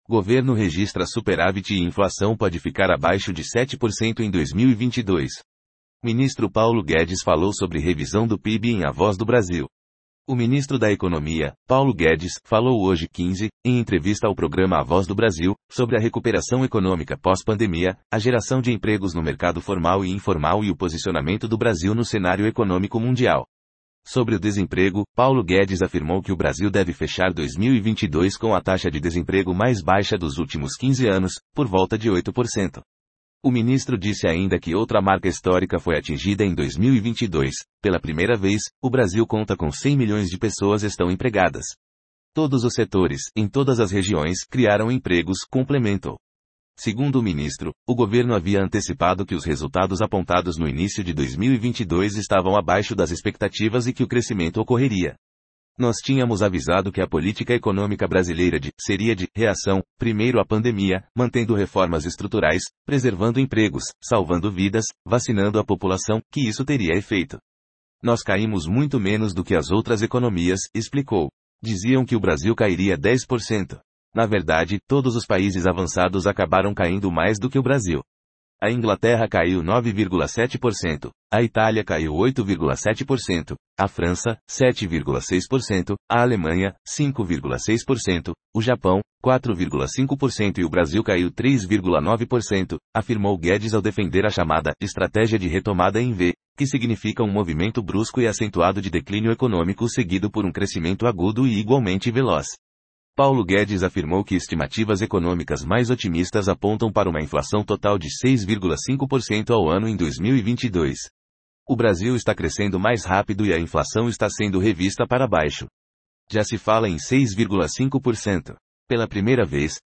Ministro da Economia, Paulo Guedes é o entrevistado no programa A Voz do Brasil